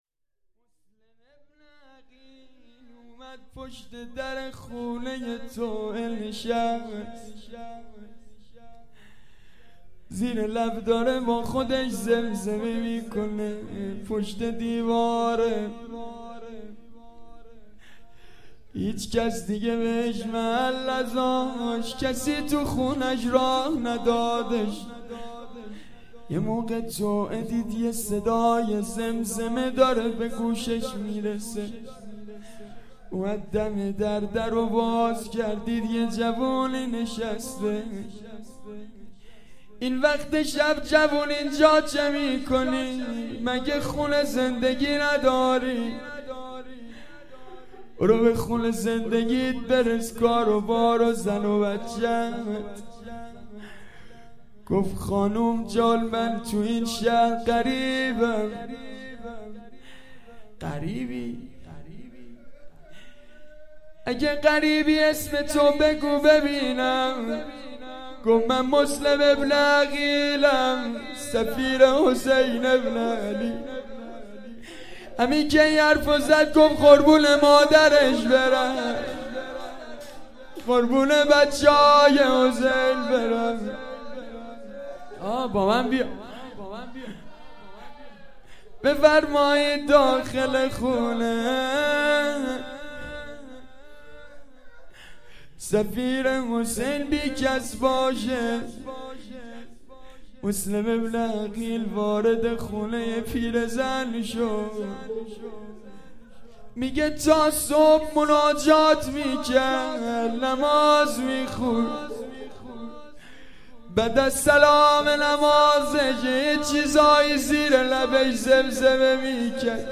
روضه مسلم ابن عقیل
محرم97 شب اول